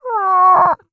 MONSTER_Cry_mono.wav